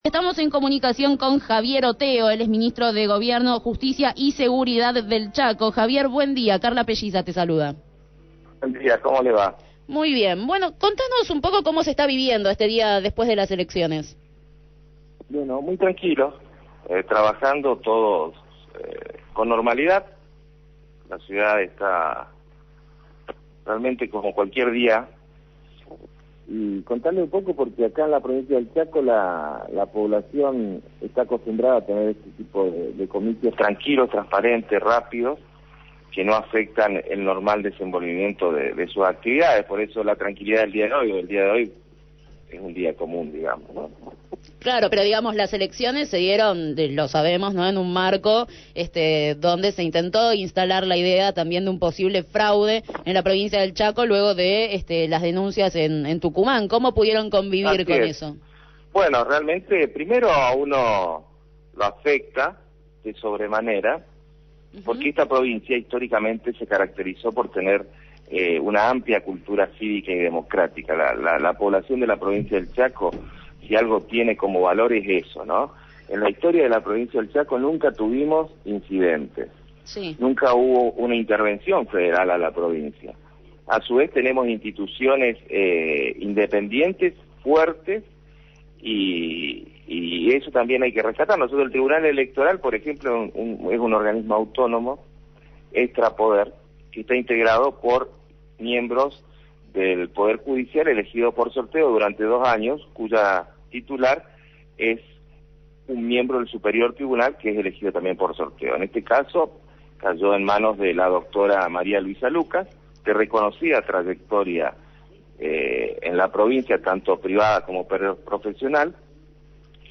Javier Oteo, Ministro de Gobierno, Justicia y Seguridad de Chaco, fue entrevistado en Desde el Barrio acerca de las elecciones provinciales en las que triunfó el Frente Chaco Merece Más (FPV) con el 55,3 % de los votos sobre el 42,3 % obtenido por el Frente Vamos Chaco.